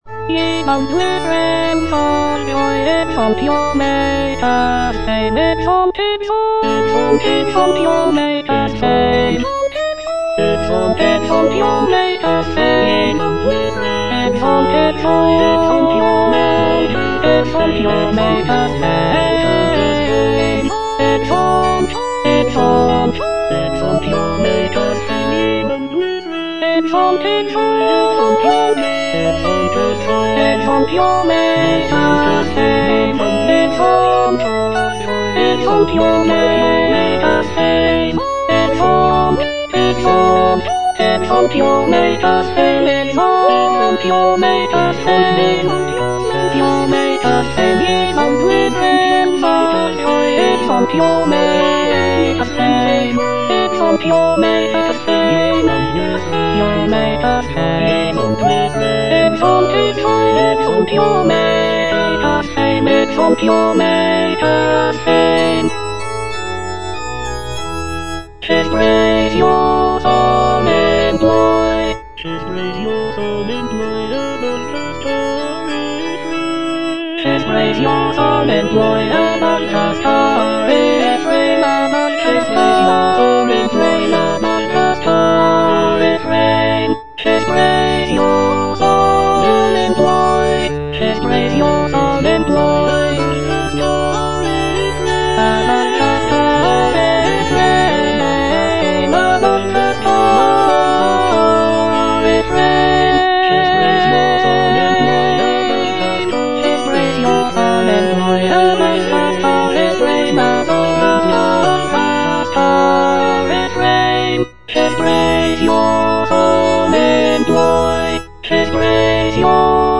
Choralplayer playing O praise the Lord with one consent - Chandos anthem no. 9 HWV254 (A = 415 Hz) by G.F. Händel based on the edition CPDL #08760
G.F. HÄNDEL - O PRAISE THE LORD WITH ONE CONSENT - CHANDOS ANTHEM NO.9 HWV254 (A = 415 Hz) Ye boundless realms of joy - Alto (Emphasised voice and other voices) Ads stop: auto-stop